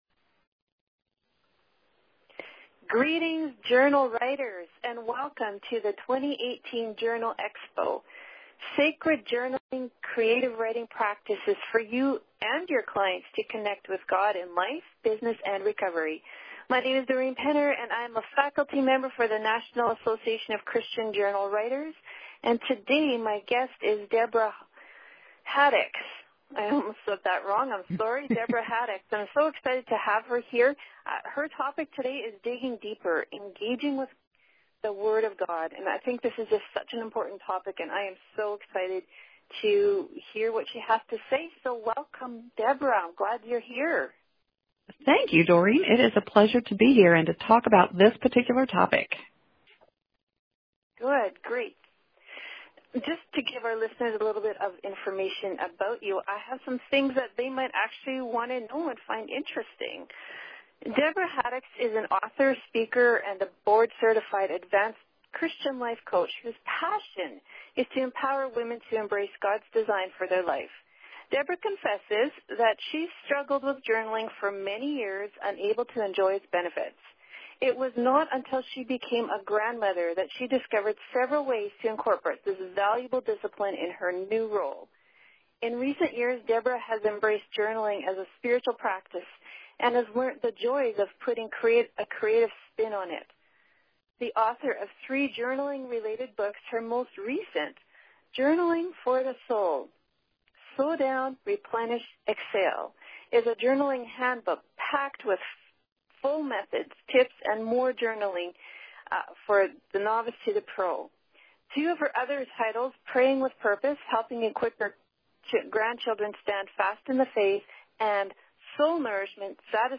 An interview on incorporating journaling into Bible reading. Broadcast as a Teleconference Session for the National Association of Christian Journal Writers.